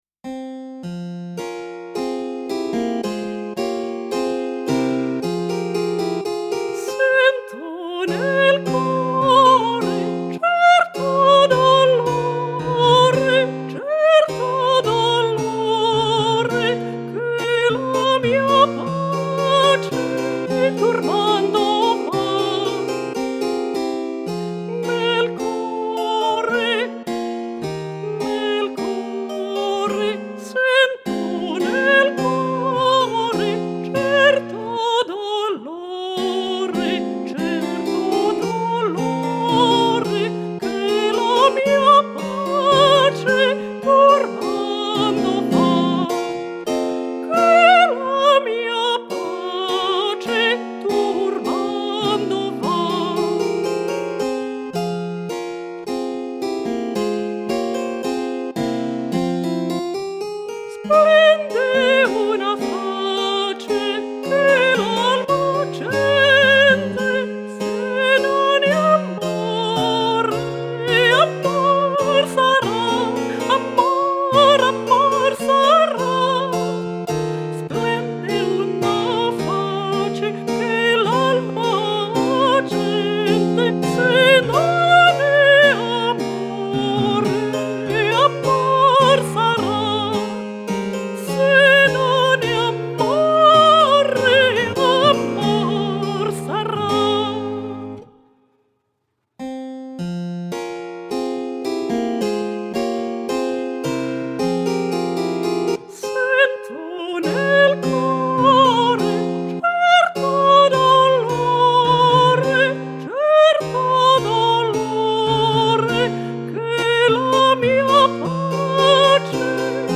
- Mezzo-soprano Soprano